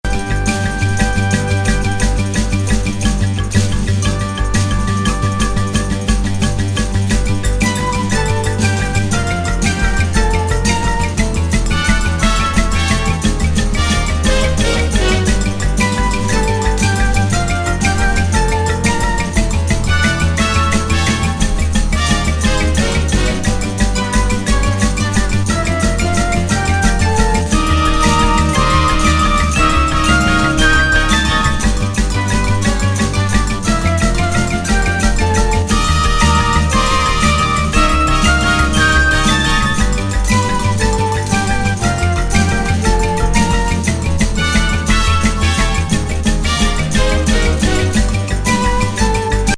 オブスキュア・サウンド。